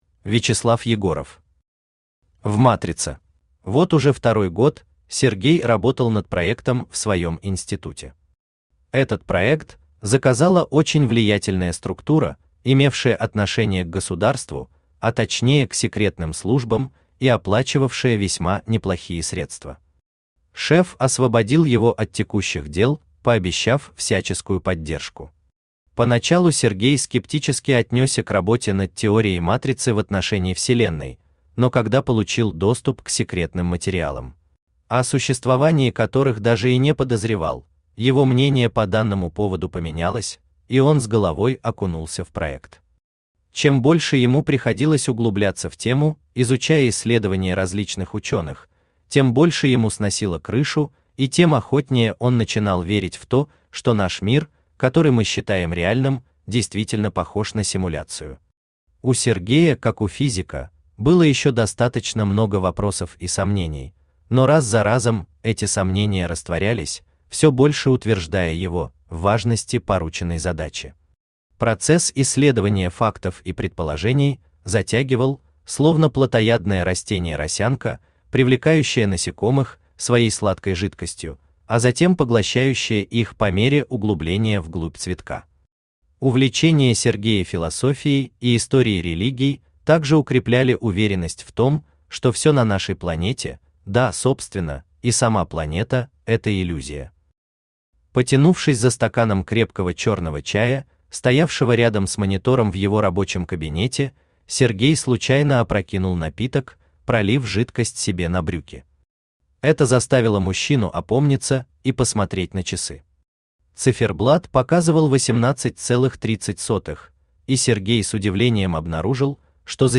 Аудиокнига В матрице | Библиотека аудиокниг
Aудиокнига В матрице Автор Вячеслав Александрович Егоров Читает аудиокнигу Авточтец ЛитРес.